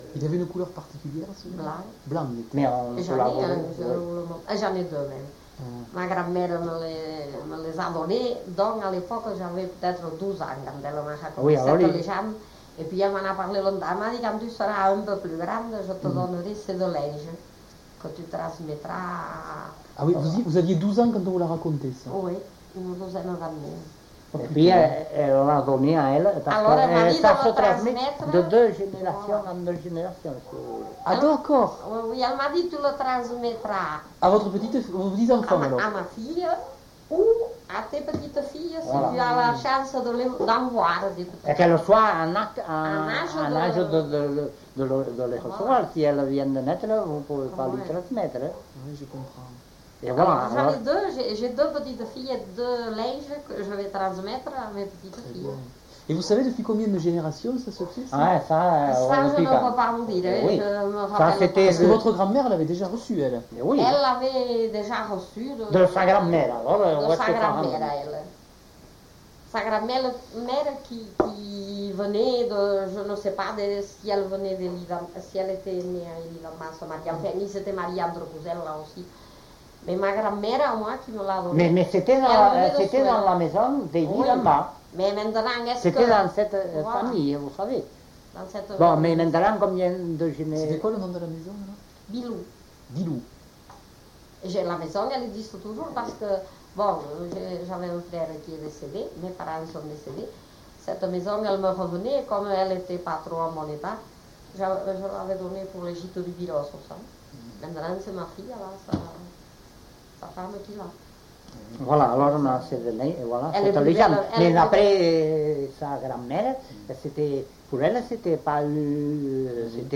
Lieu : Eylie (lieu-dit)
Genre : témoignage thématique